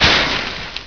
flares1.ogg